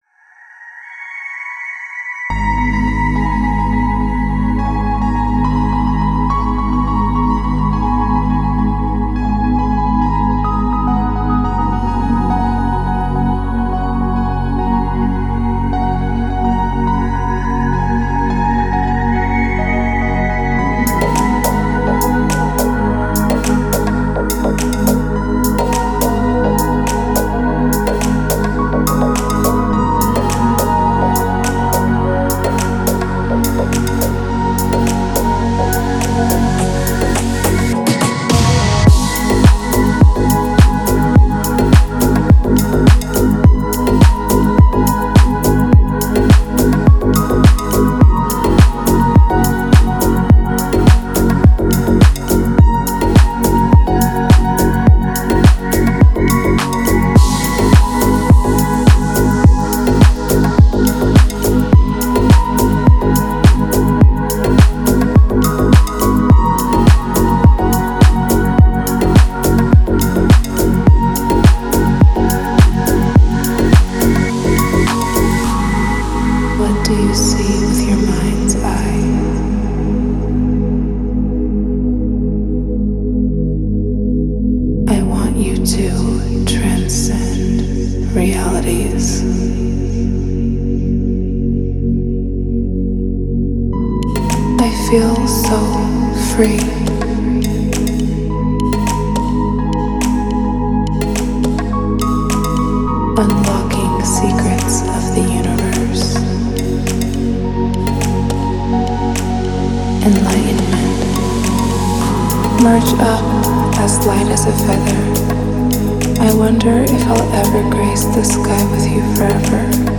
Стиль: Deep House